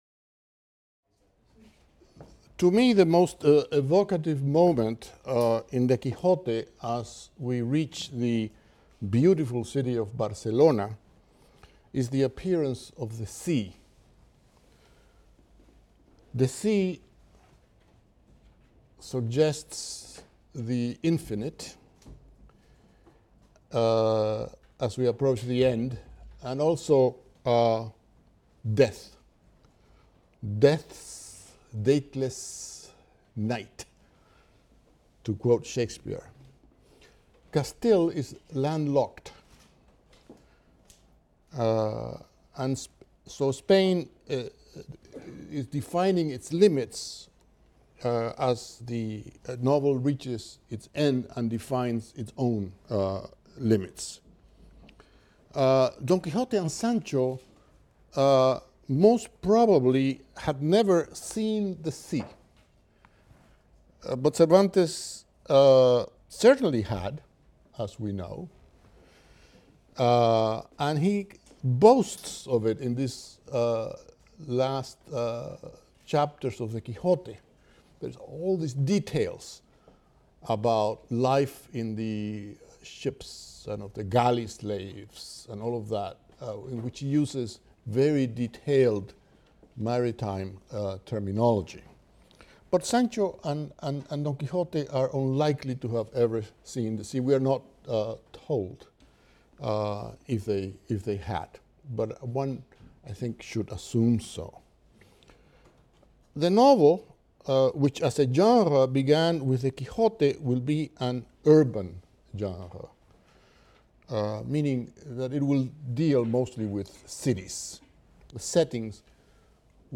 SPAN 300 - Lecture 22 - Don Quixote, Part II: Chapters LIV-LXX (cont.) | Open Yale Courses